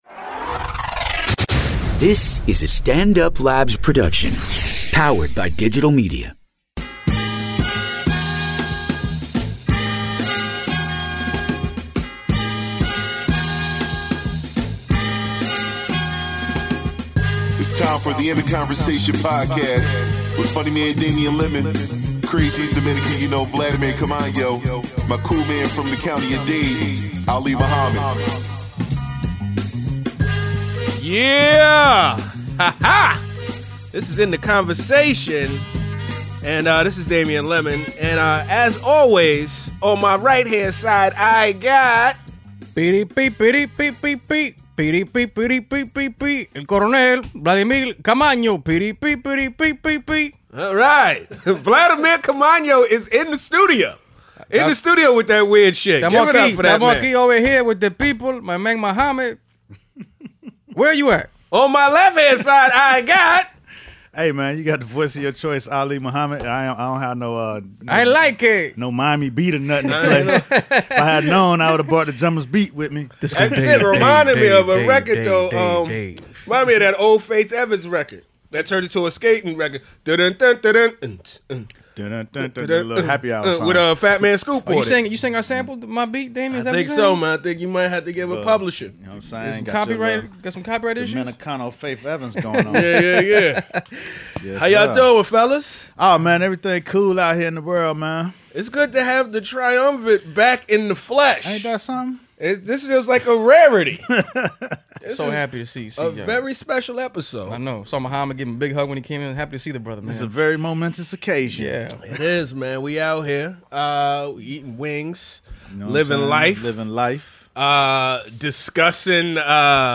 The full triumvirate is back together in studio this week to discuss SheaMoisture's controversial new commercial